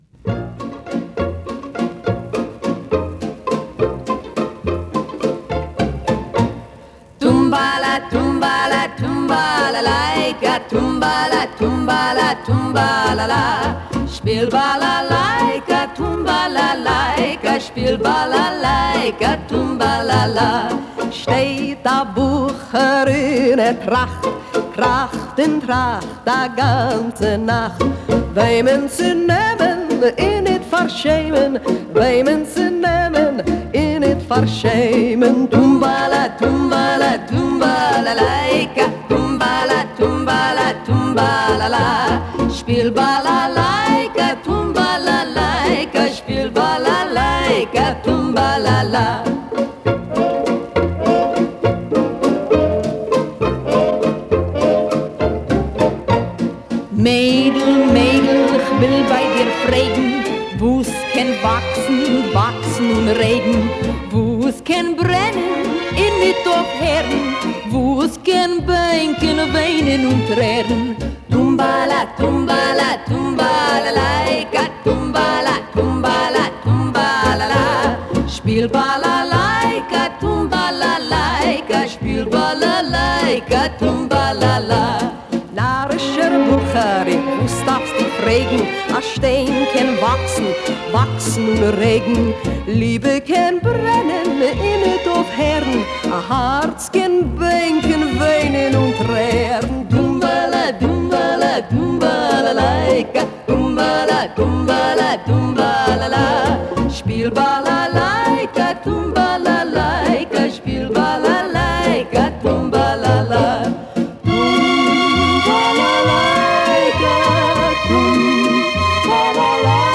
вокальном дуэте